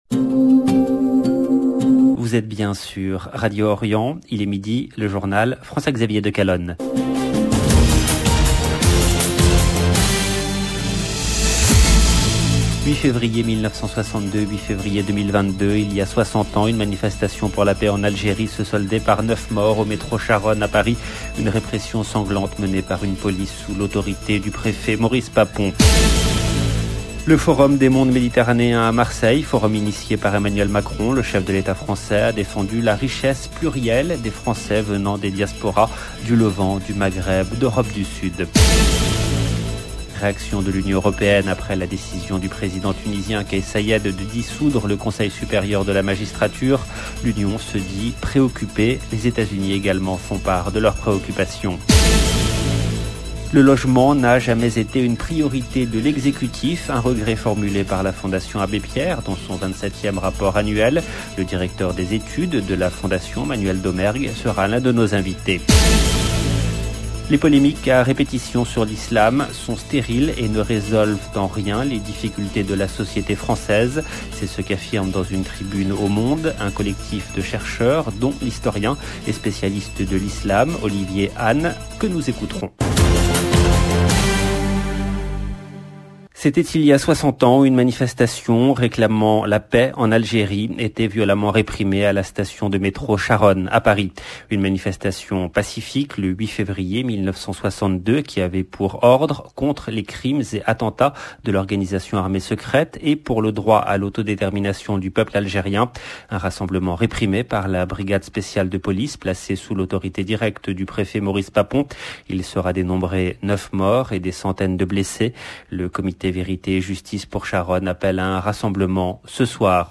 LE JOURNAL DE MIDI EN LANGUE FRANCAISE DU 8/02/22 LB JOURNAL EN LANGUE FRANÇAISE